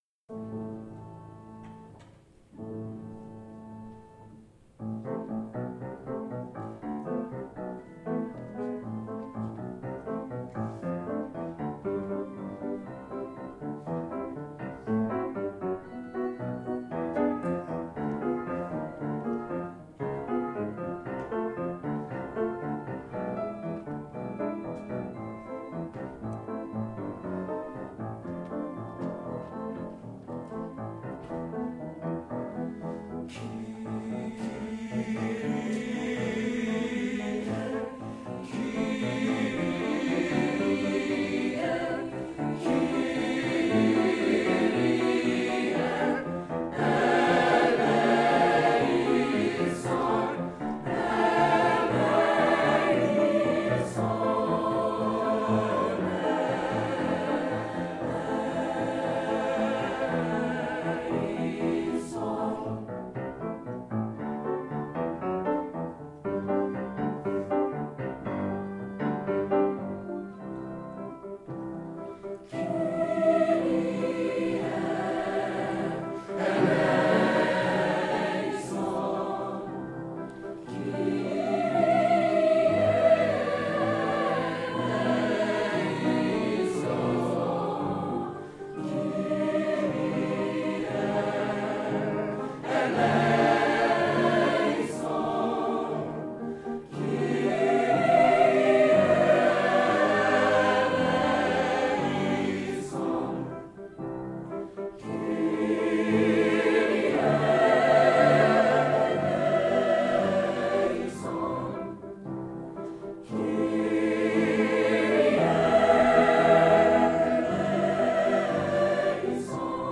Wednesday, November 12, 2010 • Roberts-Dubbs Auditorium, Brookline High School
Camerata